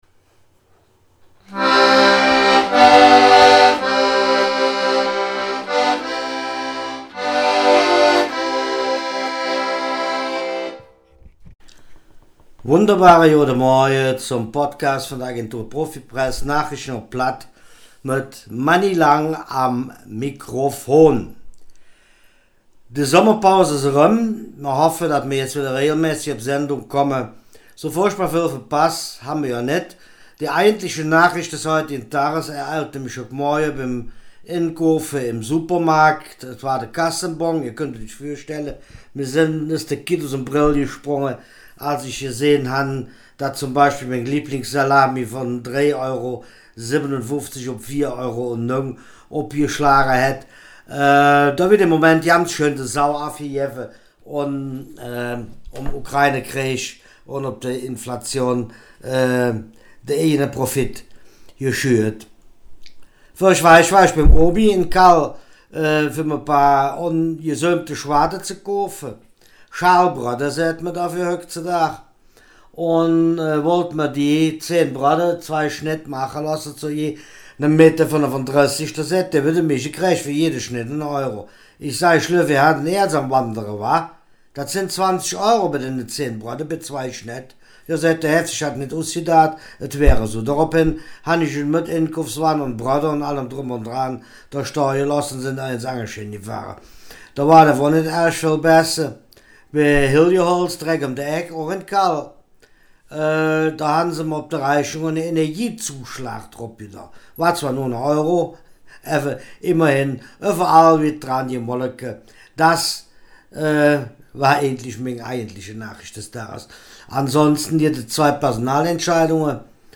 Nachrichten vom 9. August